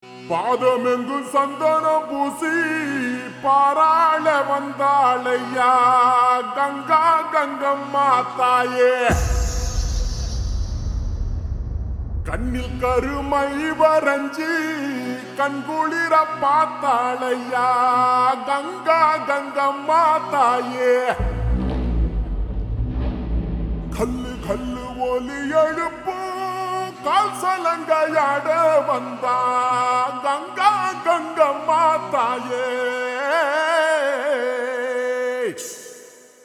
Slow Reverb Version
• Simple and Lofi sound
• Crisp and clear sound